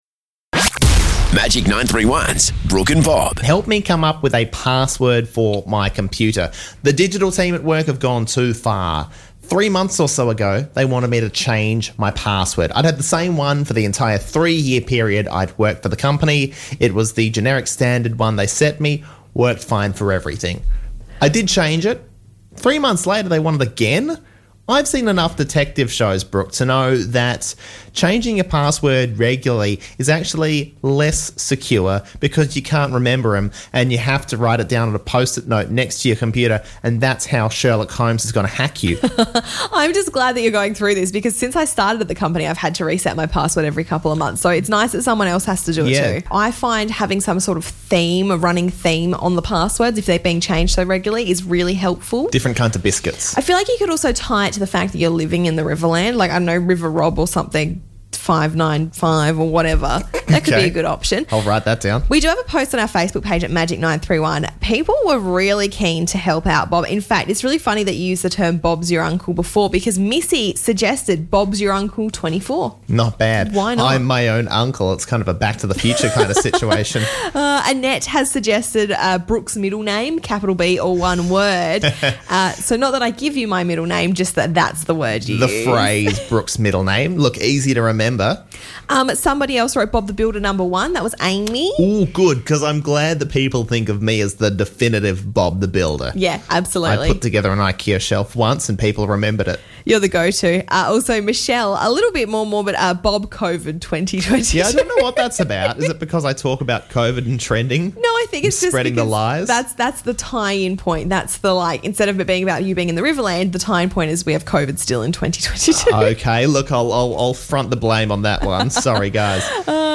... so naturally, he asked 40,000 people on live radio!